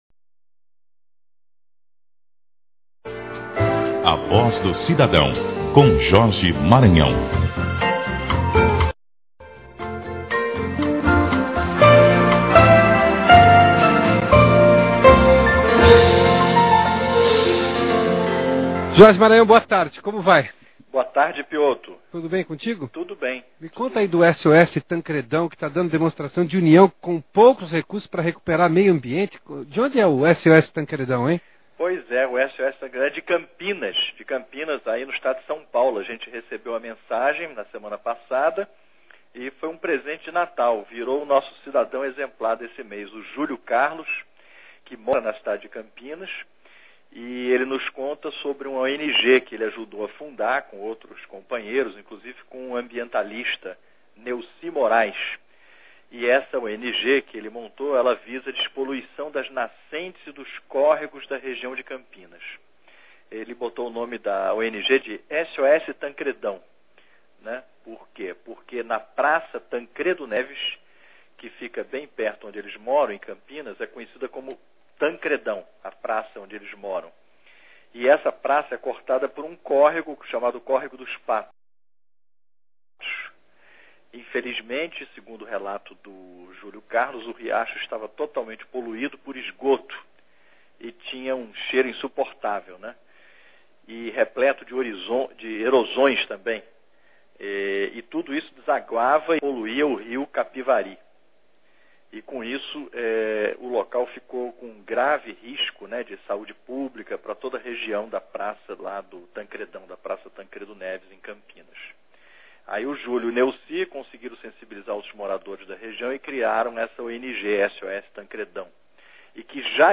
Entrevista na Rádio CBN Notícias